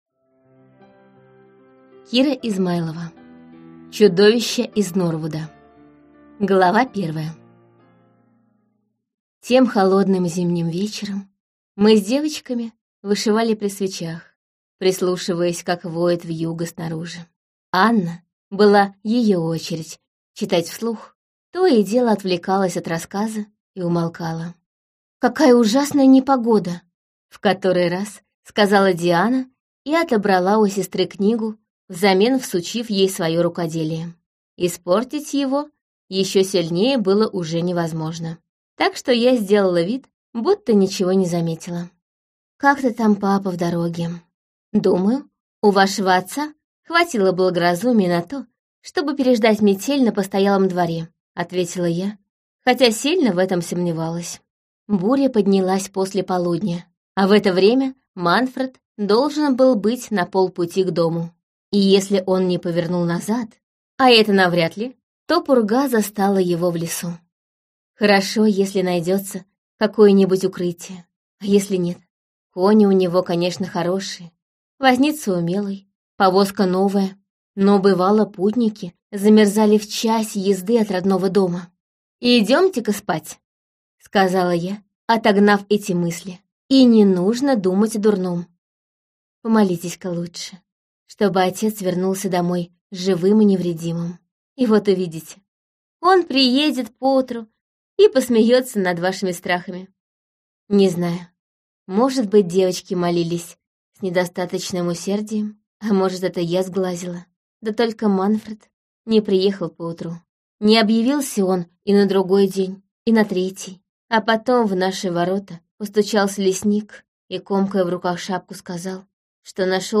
Аудиокнига Чудовища из Норвуда - купить, скачать и слушать онлайн | КнигоПоиск